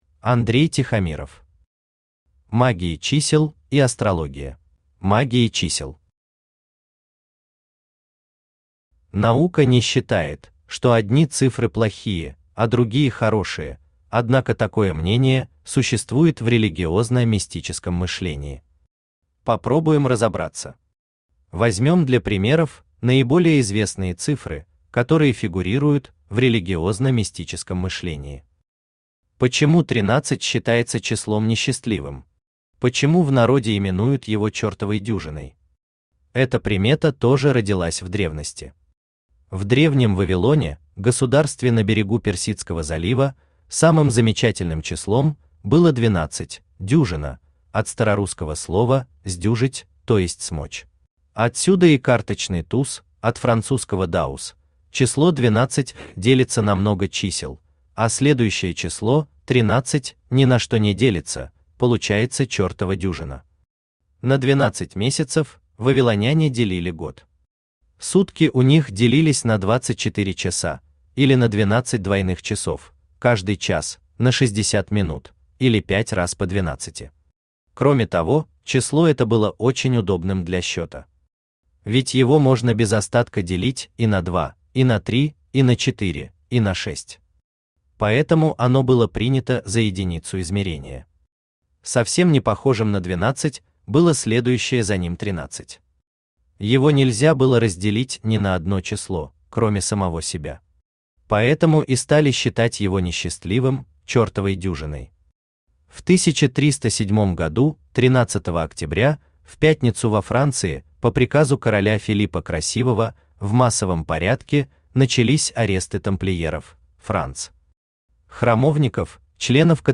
Аудиокнига Магия чисел и астрология | Библиотека аудиокниг